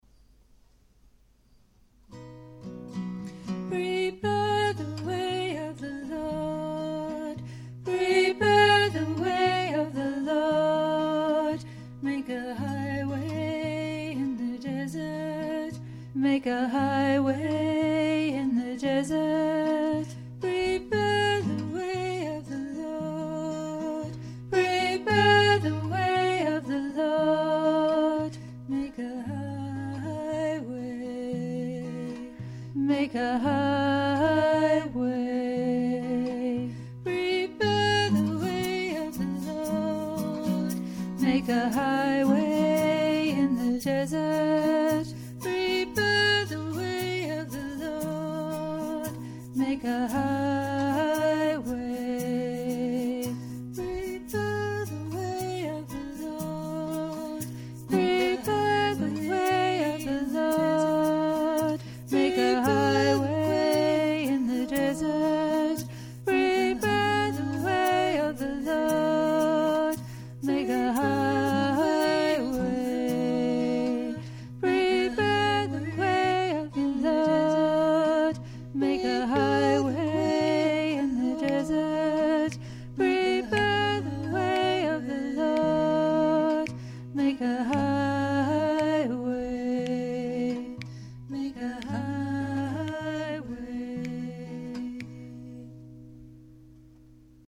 Shorter, more meditative, songs